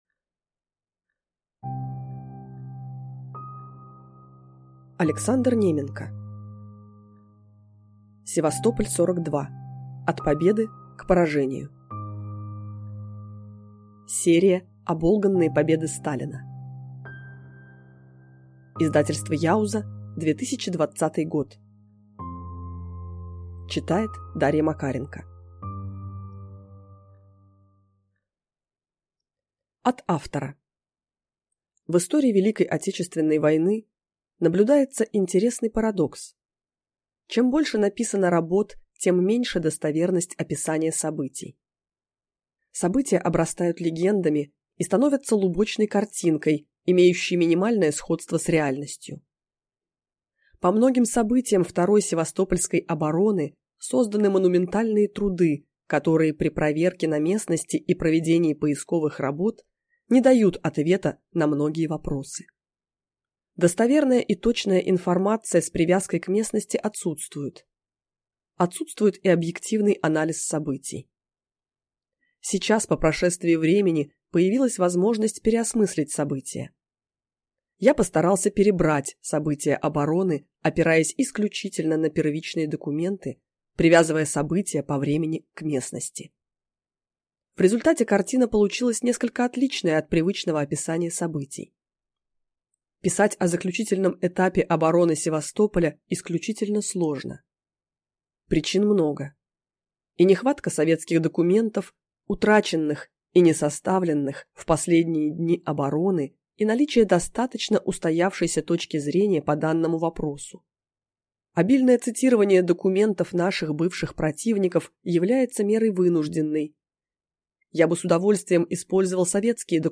Аудиокнига Севастополь-42. От победы к поражению | Библиотека аудиокниг